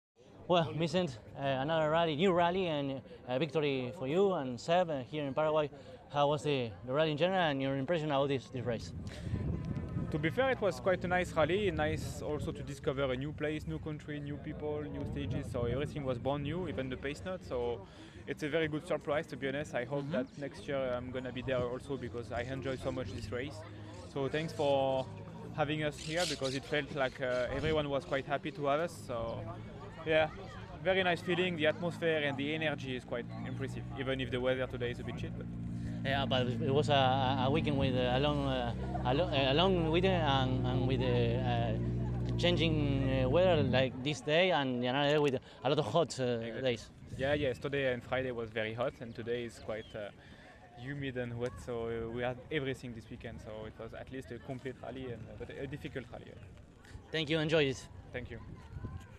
Así entonces, a continuación, podrás escuchar las entrevistas que realizamos (en inglés) con cada uno de ellos: